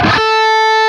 LEAD A 3 LP.wav